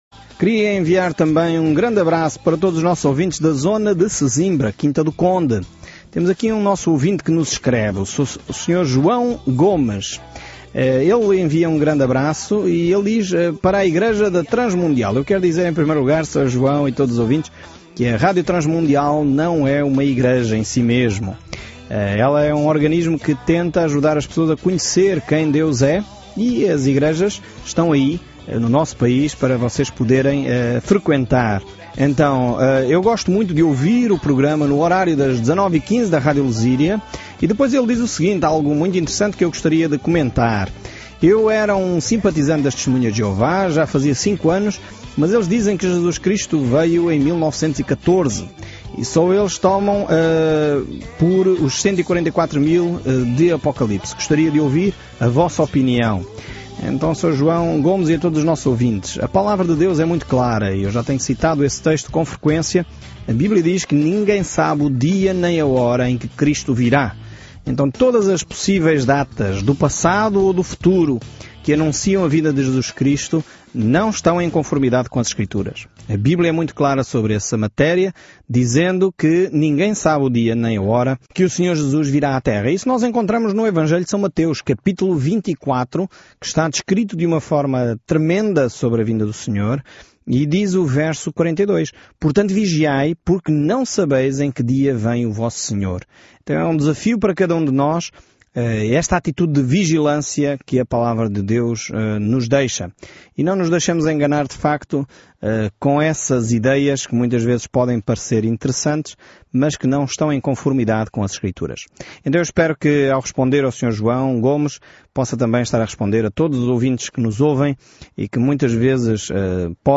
Escritura AGEU 1:1 Iniciar este Plano Dia 2 Sobre este plano A atitude de Ageu de “fazer isso” incita um Israel distraído a reconstruir o templo depois de retornar do cativeiro. Viaje diariamente por Ageu enquanto ouve o estudo em áudio e lê versículos selecionados da palavra de Deus.